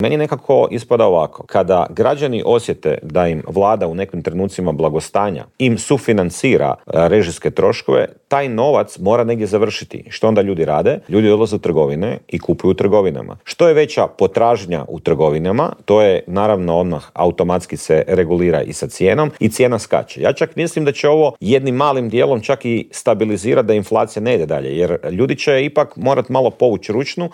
ZAGREB - "Bilo je i vrijeme da se gospodarske mjere relaksiraju, građani sada moraju preuzeti dio tereta na sebe. To neće ubrzati rast inflacije, a važno je da pomognemo onima koji su najugroženiji", u Intervjuu tjedna Media servisa poručio je saborski zastupnik iz redova HSLS-a Dario Hrebak.